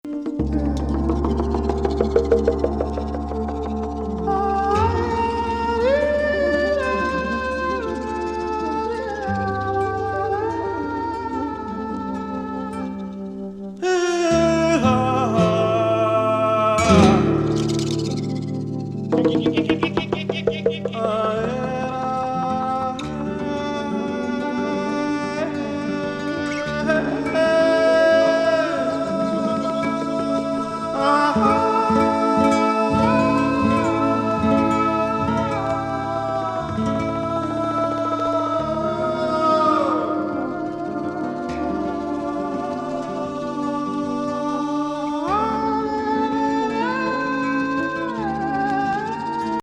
他のブラジル音楽とは異色の、独特の生命力を匂わせるミナス音楽。